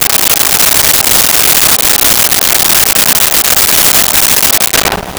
Lofi Drum Roll 03
LoFi Drum Roll 03.wav